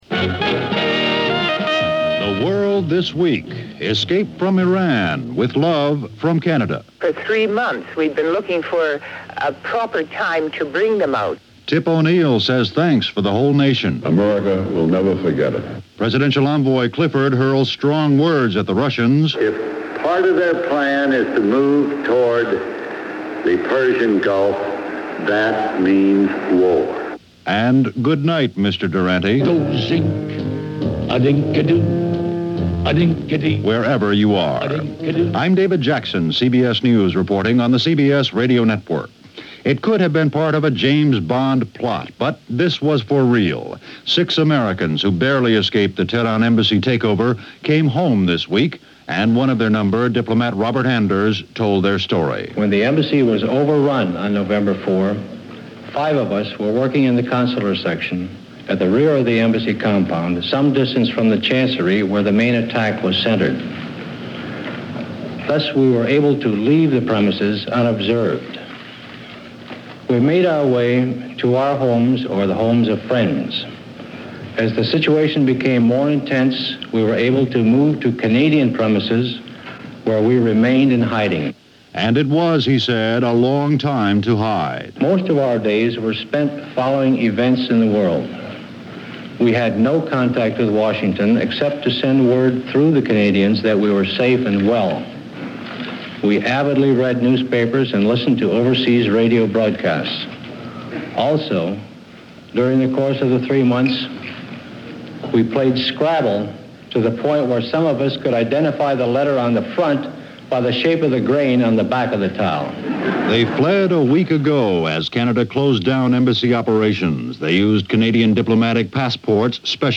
– CBS Radio: The World This Week – February 3, 1980 – Gordon Skene Sound Collection –
All that, along with heaps of love to Canada and a lot more for the week that ended on this February 3rd in 1980, as presented by CBS Radio‘s The World This Week.